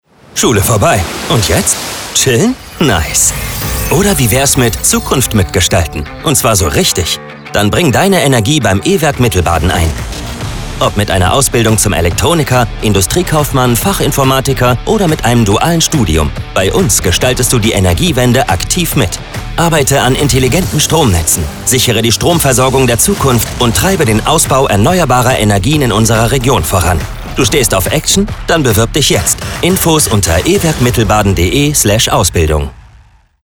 Und so klingen die Spots in der finalen Tonmischung:
Werbespot V1